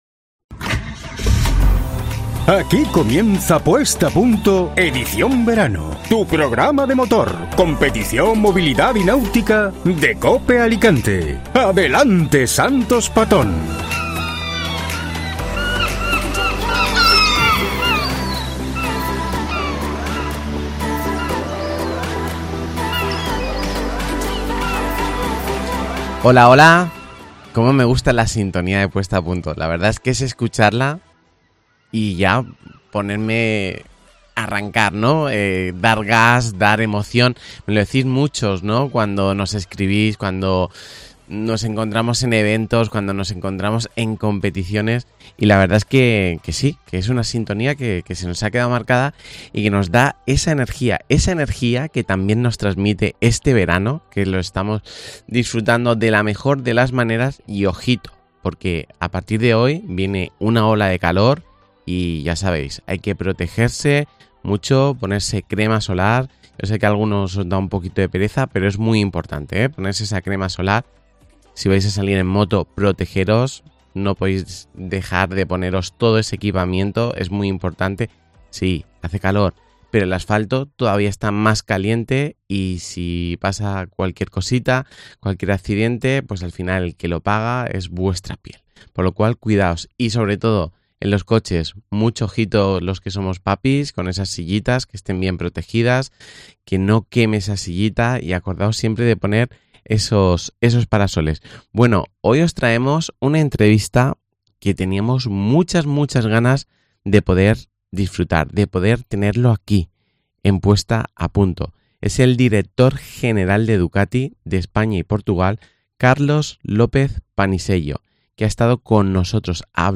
Una entrevista que nos da las claves del gran momento que esta viviendo la marca, y elpresente y futuro, de una marca que se vive con pasión.